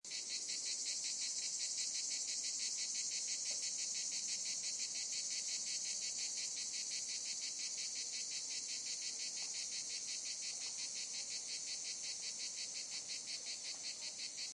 cicada.mp3